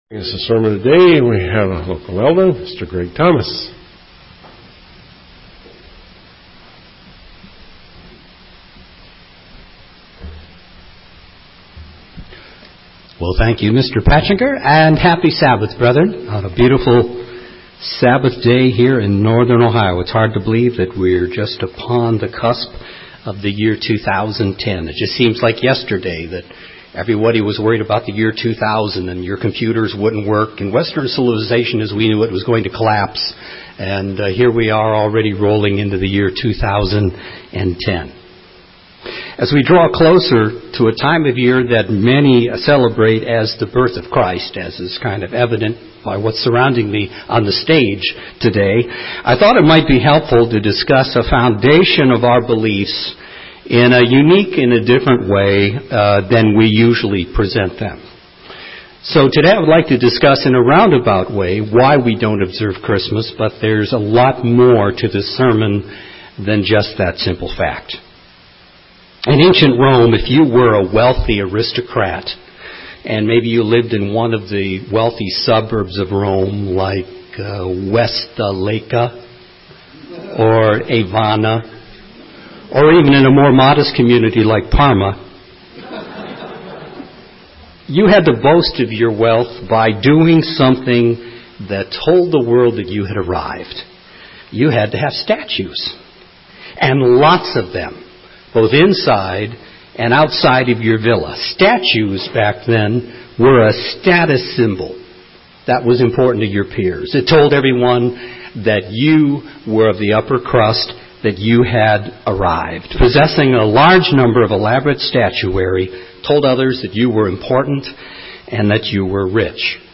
This is the sermon today.